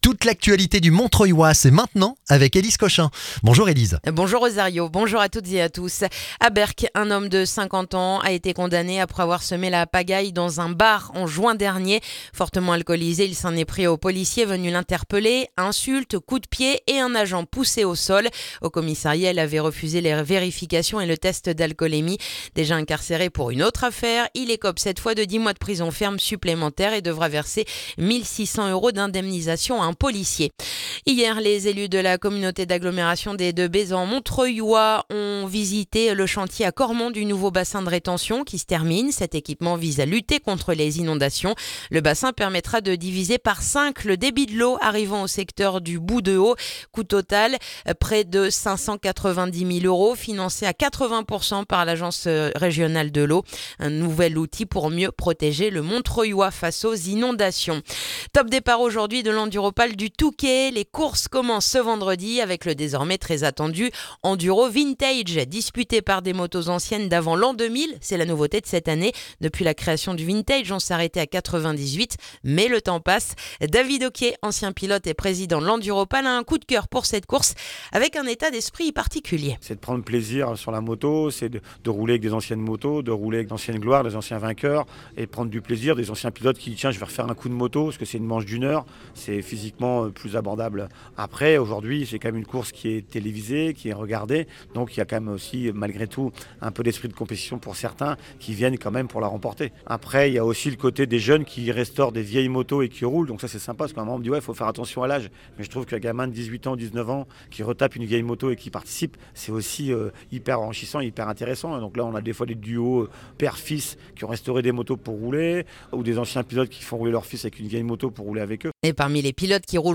Le journal du vendredi 13 février dans le montreuillois